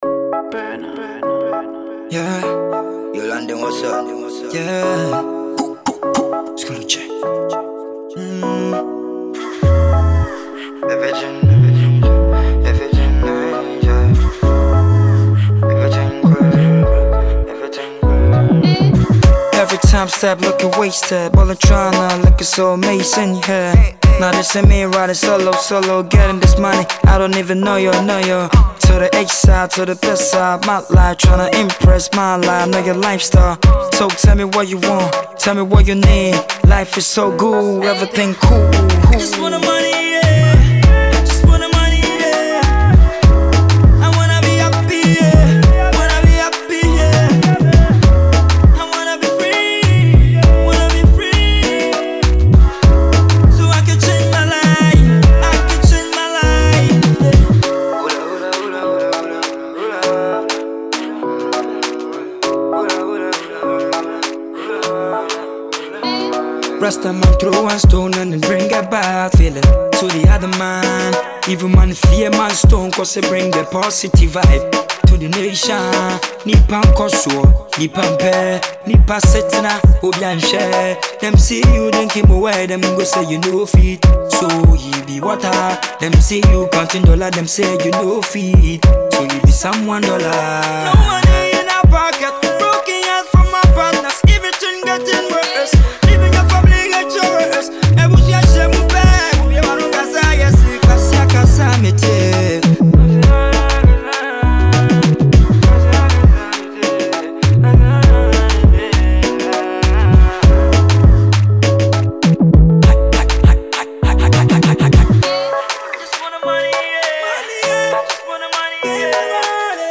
Afro Pop